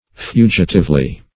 Meaning of fugitively. fugitively synonyms, pronunciation, spelling and more from Free Dictionary.
fugitively - definition of fugitively - synonyms, pronunciation, spelling from Free Dictionary Search Result for " fugitively" : The Collaborative International Dictionary of English v.0.48: Fugitively \Fu"gi*tive*ly\, adv. In a fugitive manner.